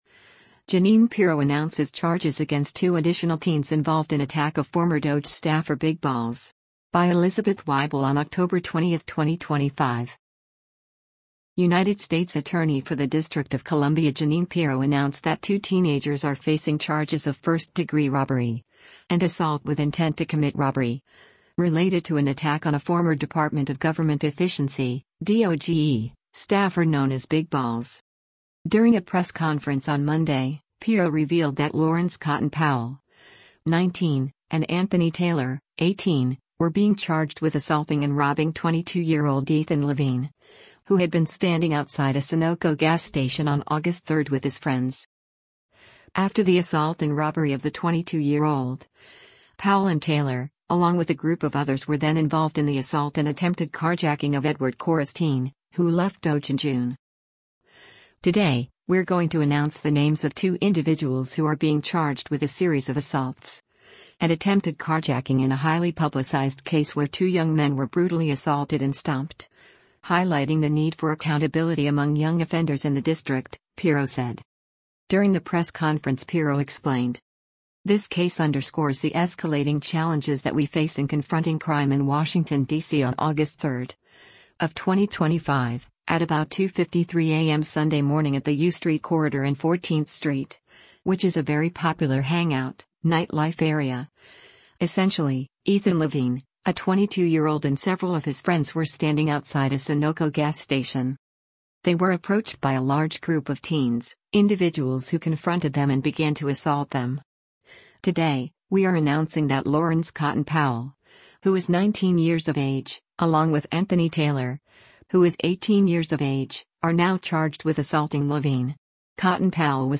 During the press conference Pirro explained: